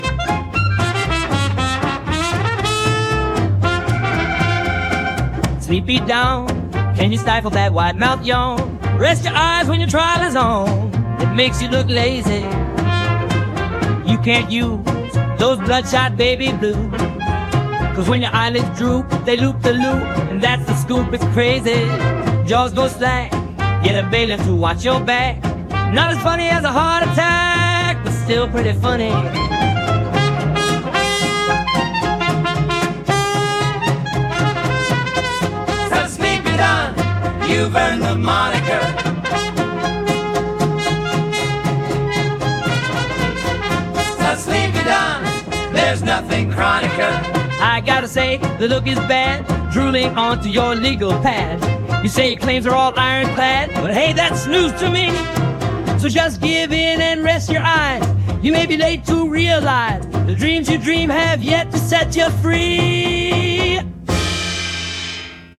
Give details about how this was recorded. Rare Extended Outtake Track